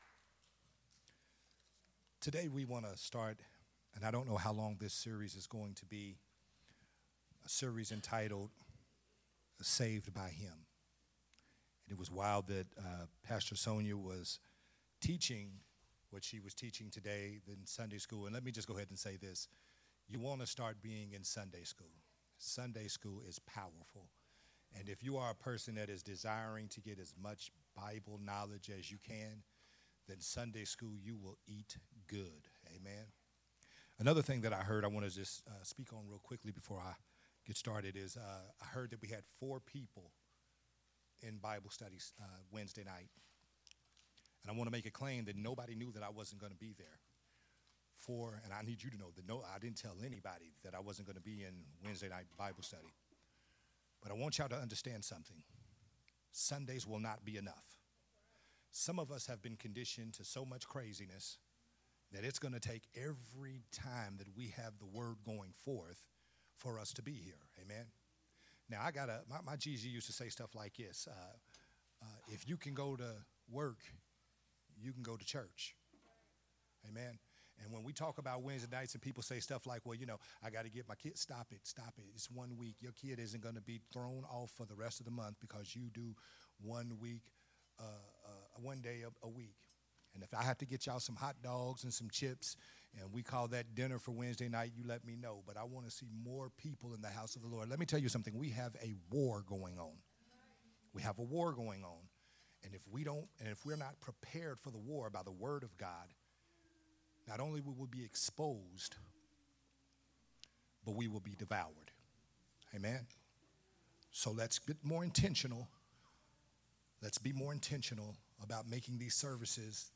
sermon series
recorded at Unity Worship Center